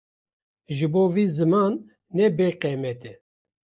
Pronounced as (IPA) /zɪˈmɑːn/